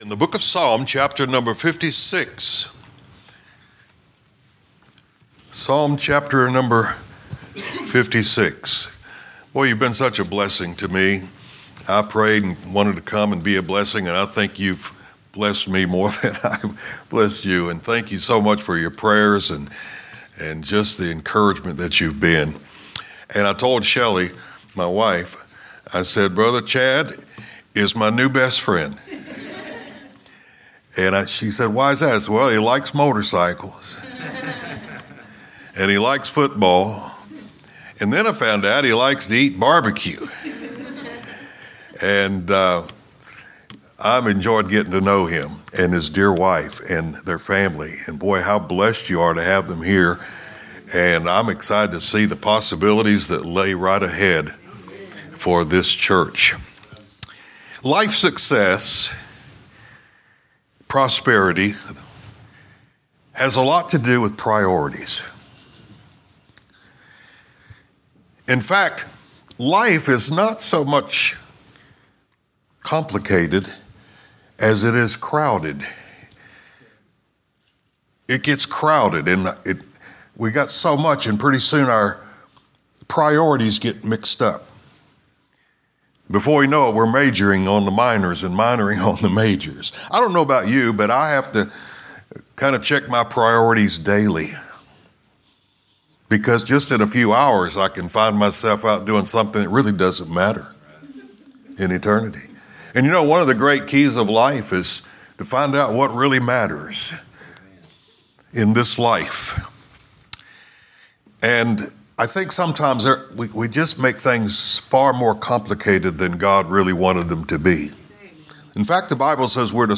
Sermons preached during our annual missions revival